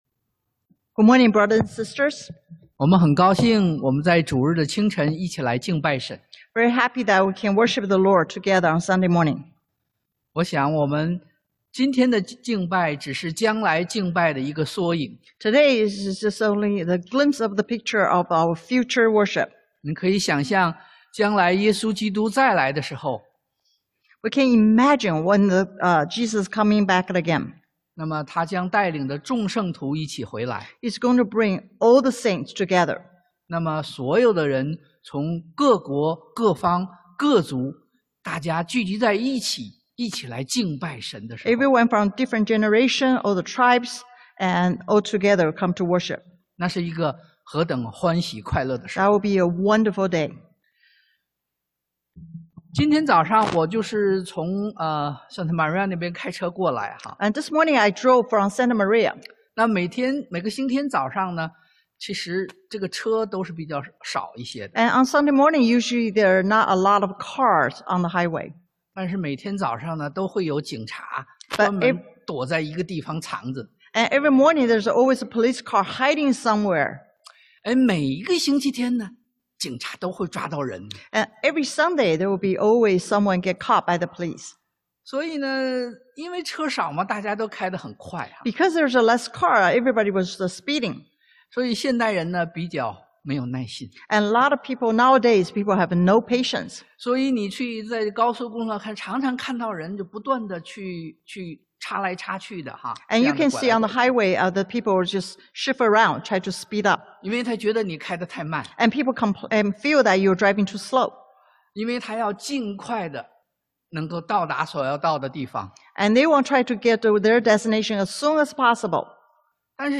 創 Gen 16:1-16 Service Type: Sunday AM 忍耐等候 Wait for God Patiently 經文Passage